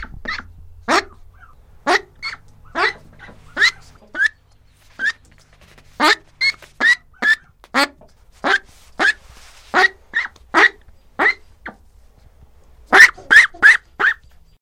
01 鸭子和母鸡；嘎嘎的咯咯声；接近；农场
描述：嘎嘎叫;咯咯;关;在一个小木屋里面;农舍;鸭母鸡;
Tag: 第四纪该死 母鸡 咯咯叫 农场 里面 房子